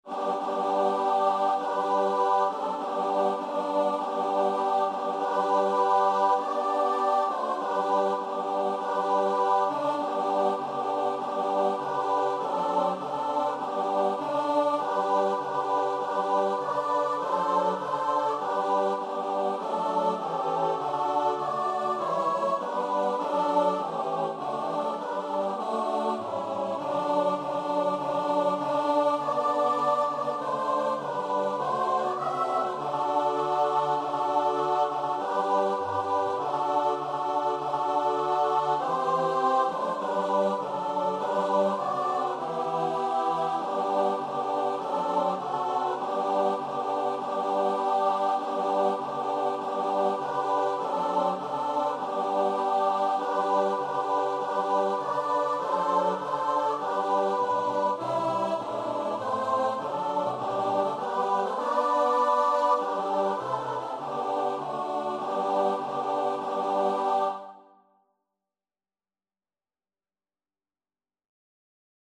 Choir version
4/4 (View more 4/4 Music)
Choir  (View more Intermediate Choir Music)
Classical (View more Classical Choir Music)